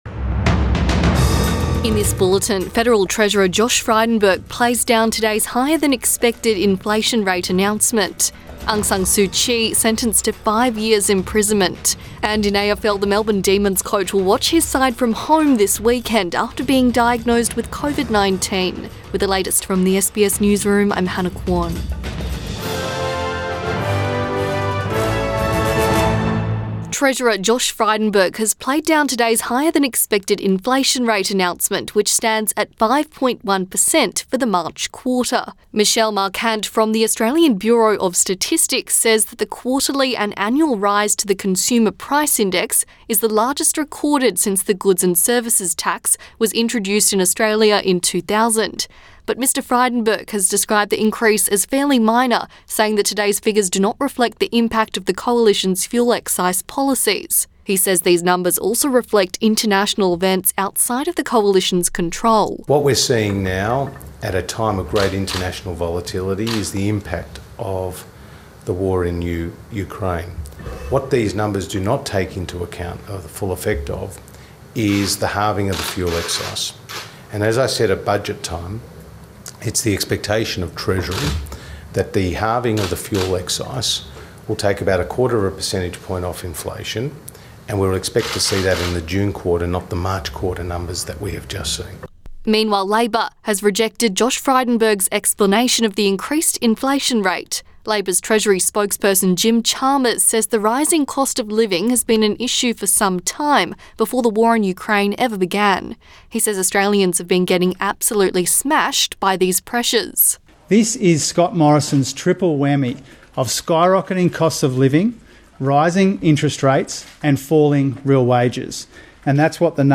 PM bulletin 27 April 2022